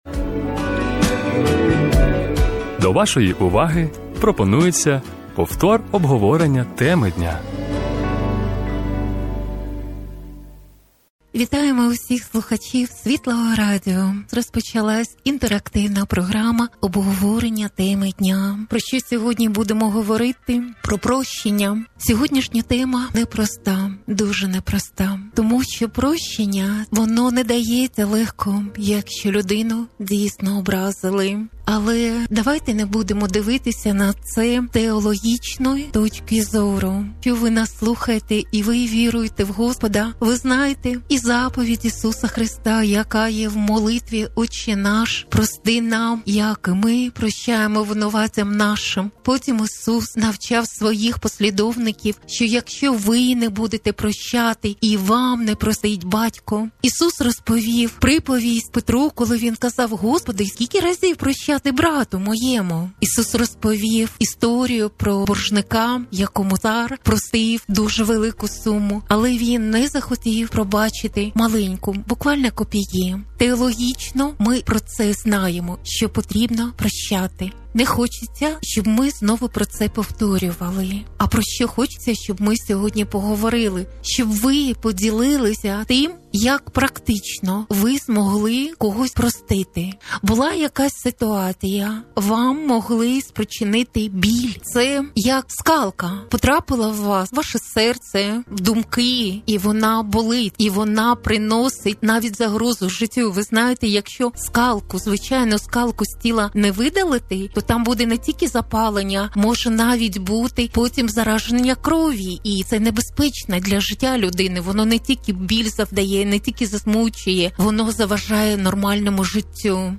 15.11.25 - ПРОЩЕННЯ - Свідчення Слухачів, Практичні Поради. | Архів Світлого Радіо
ЯК ПРОСТИТИ - це практика й дуже часто без Божої допомоги не подолати образу. В програмі слухачі ділились власним досвідом ЯК ВОНИ ЗМОГЛИ ПРОСТИТИ тих, хто їх образив.